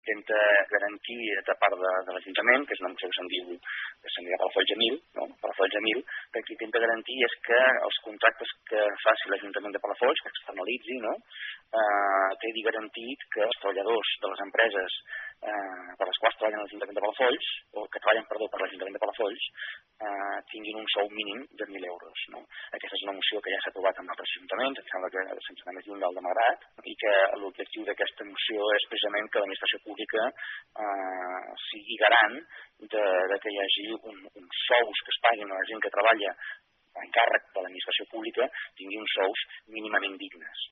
El grup d’Esquerra de Palafolls presentarà en el proper ple una moció titulada “Palafolls a mil” amb l’objectiu de garantir que els treballadors de les empreses amb les que l’Ajuntament hi té serveis externalitzats cobrin un salari mínim de mil euros. Ho explica Francesc Alemany, portaveu d’ERC a l’Ajuntament de Palafolls.